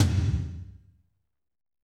TOM F RLO1BR.wav